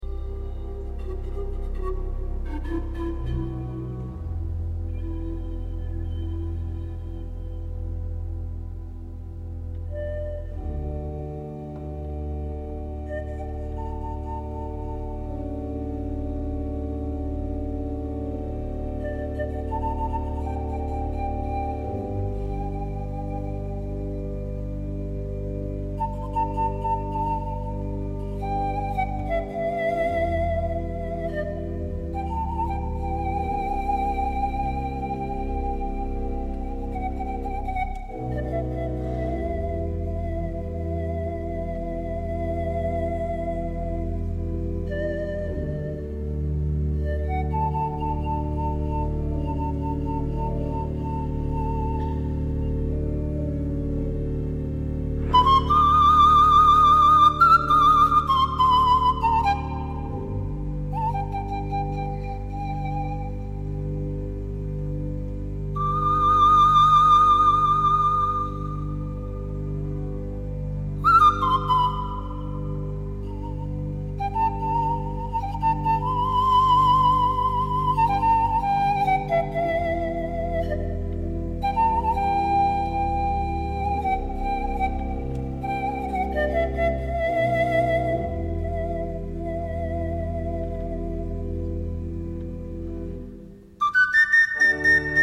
La dinamica risultante è spettacolare!